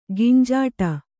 ♪ ginjāṭa